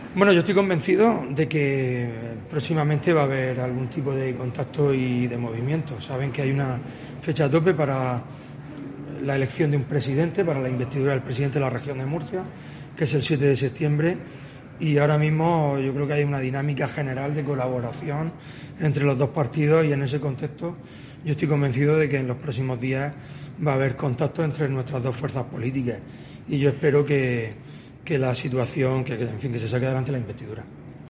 En declaraciones a los medios, el alcalde de Lorca, el 'popular' Fulgencio Gil, expresó este jueves su convencimiento de que "próximamente" habrá "algún tipo de contacto y movimiento".